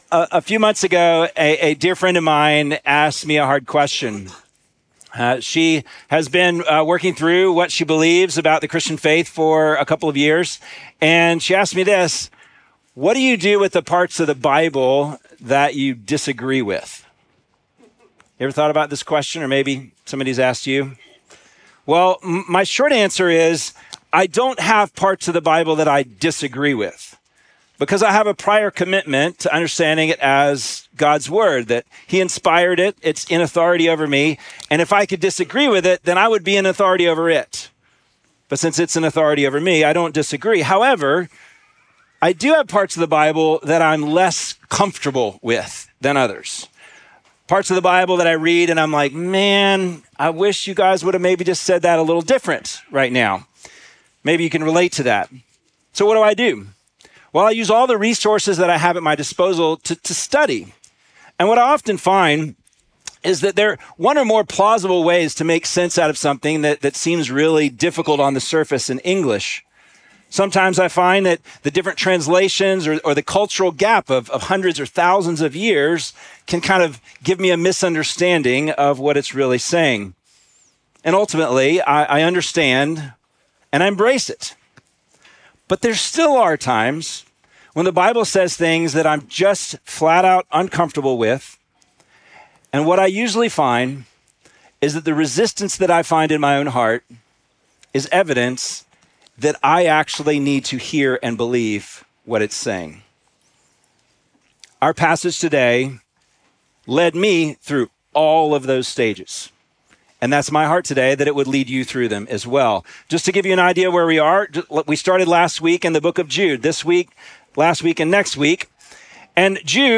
Sermons from Atlanta Westside Presbyterian Church.